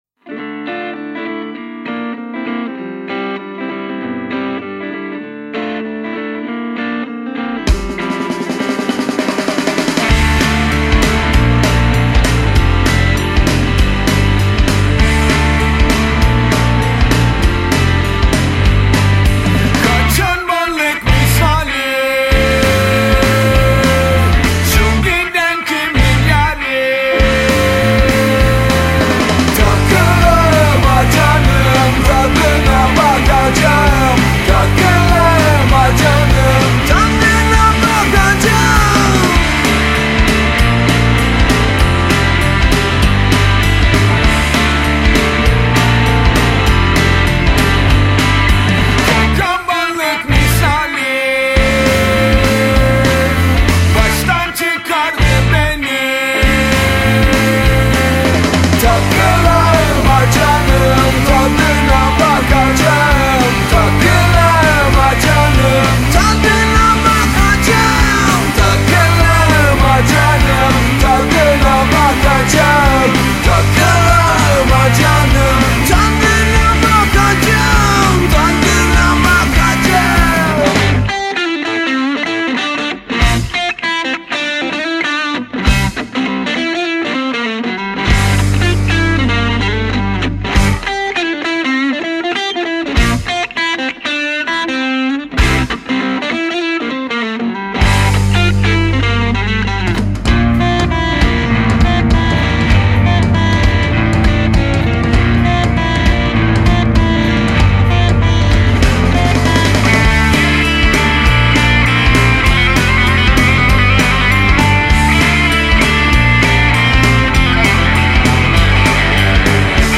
Ancaq rock janrinda mp3-ler.